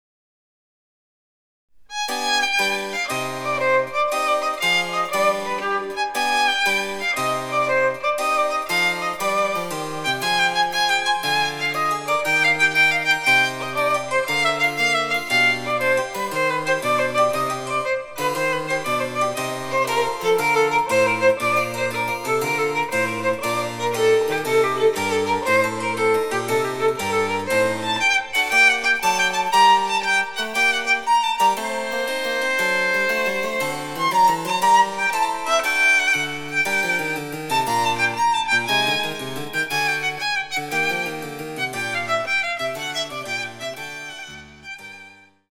■ヴァイオリンによる演奏
ヴァイオリン演奏
チェンバロ（電子楽器）演奏